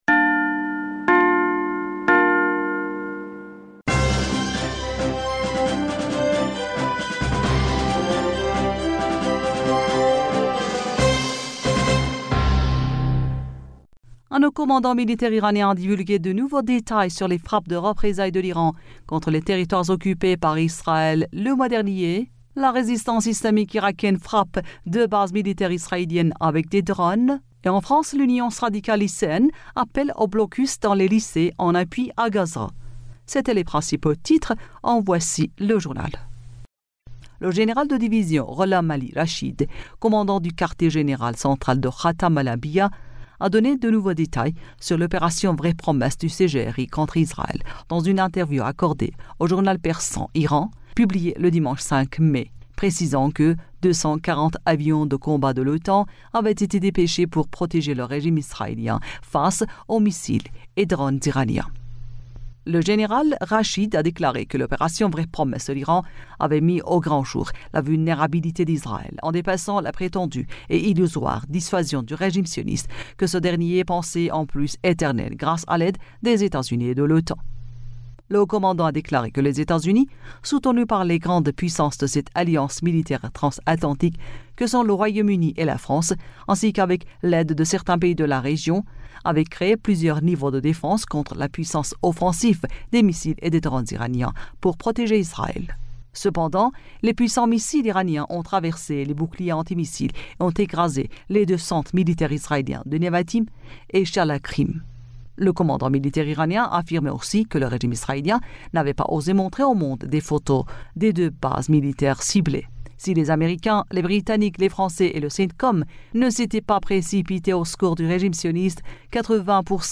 Bulletin d'information du 06 Mai